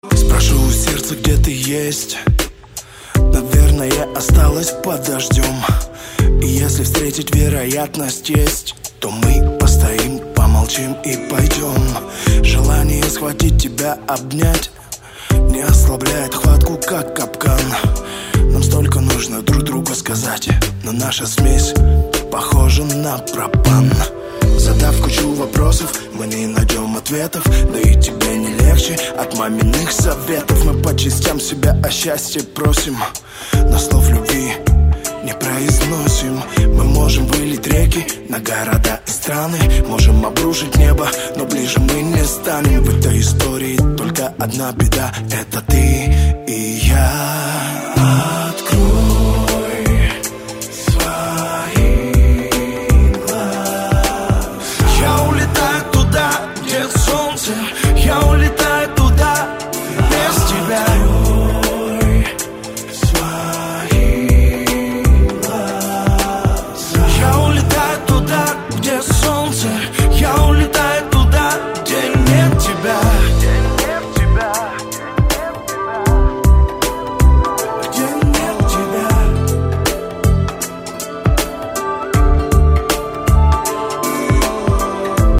• Качество: 192, Stereo
мужской вокал
лирика
спокойные